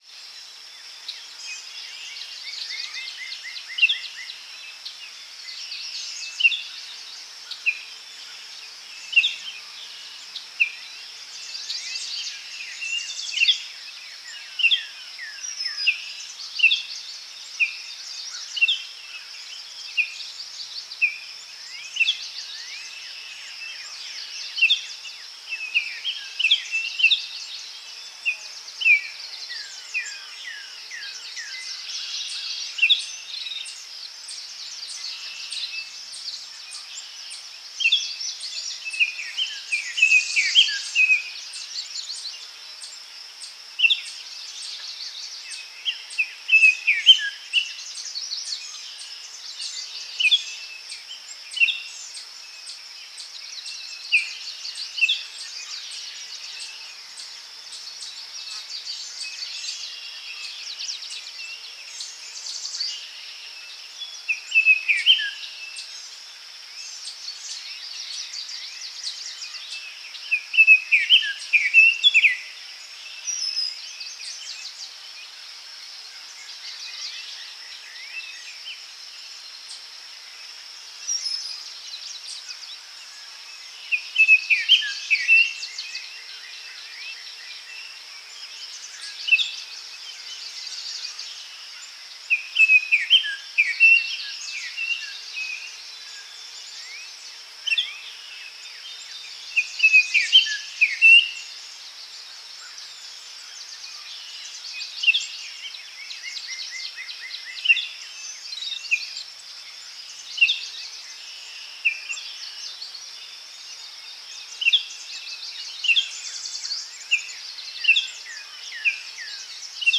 forest-day-3.ogg